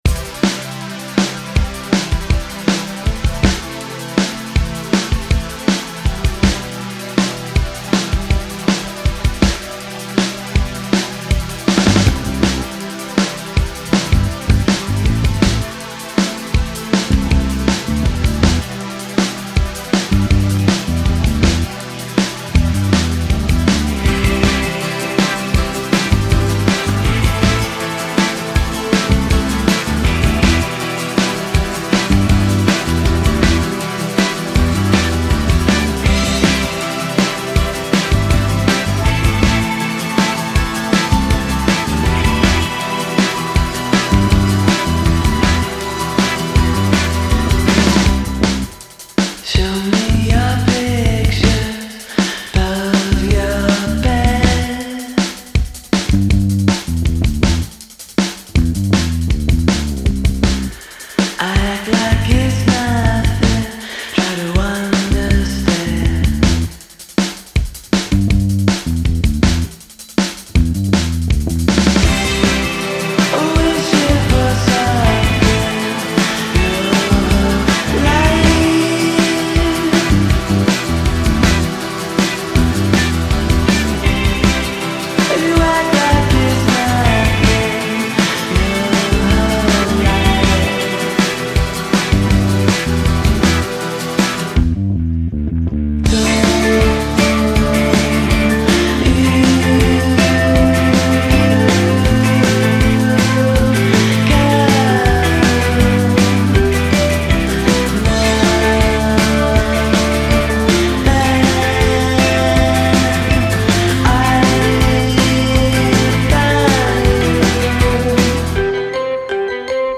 bass
guitar
drums